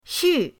xu4.mp3